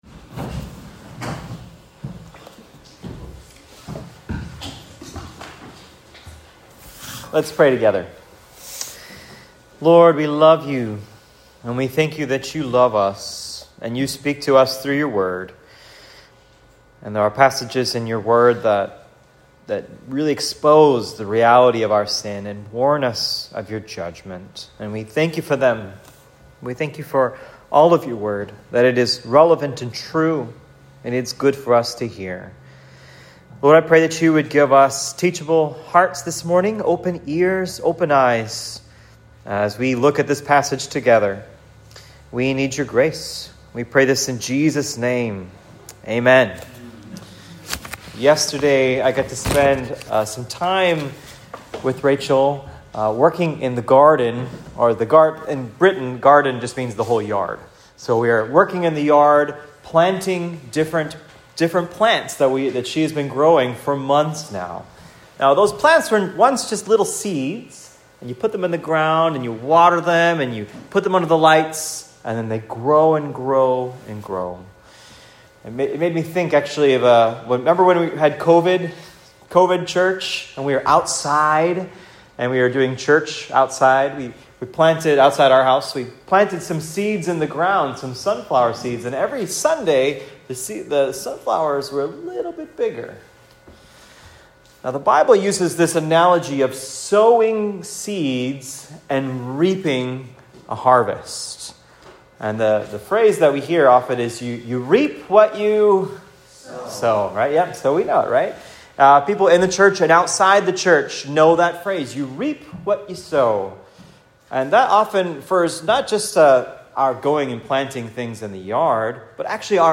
“Reap What You Sow” (Hosea 8-10 Sermon)